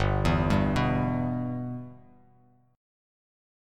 G6 Chord
Listen to G6 strummed